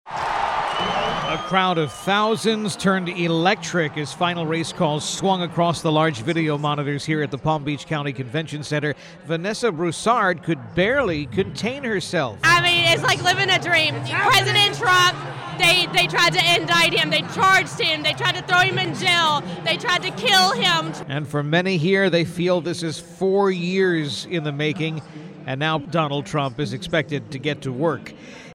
SUPPORTERS OF DONALD TRUMP WILL NO LONGER CALL HIM “FORMER PRESIDENT.” WE HAVE MORE NOW, COURTESY OF FOX NEWS FROM TRUMP ELECTION NIGHT HEADQUARTERS IN WEST PALM BEACH…